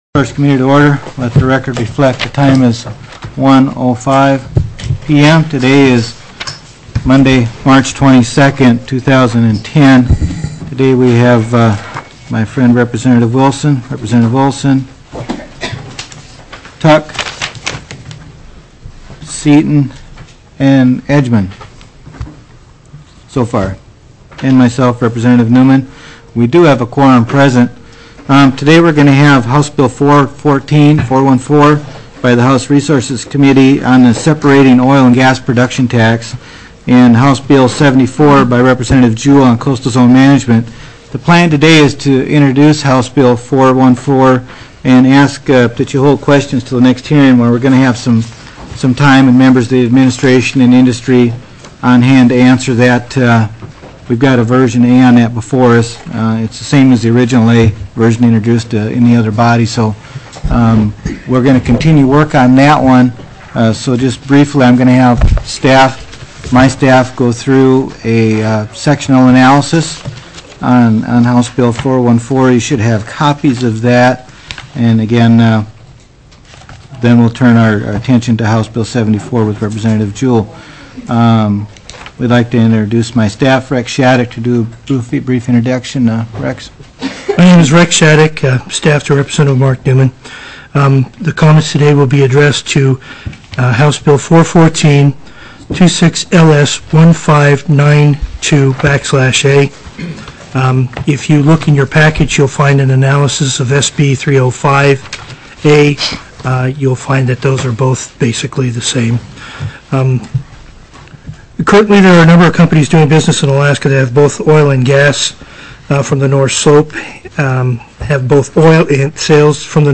TELECONFERENCED